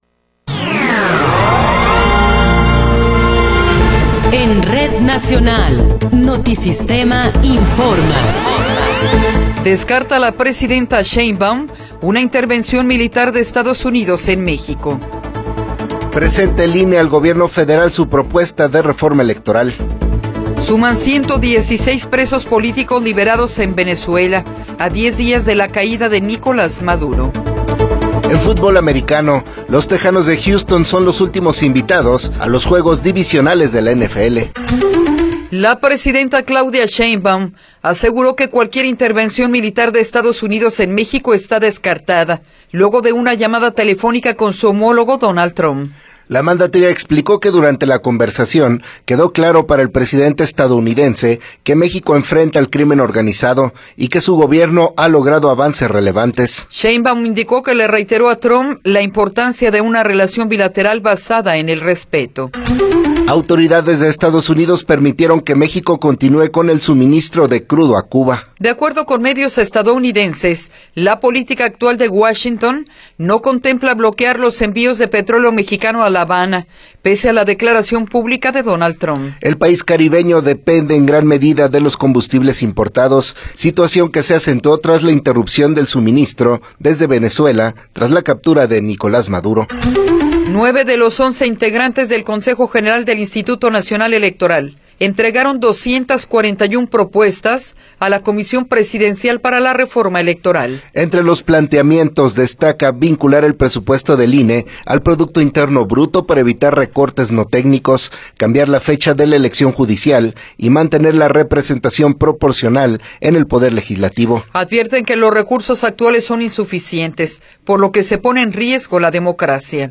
Noticiero 8 hrs. – 13 de Enero de 2026
Resumen informativo Notisistema, la mejor y más completa información cada hora en la hora.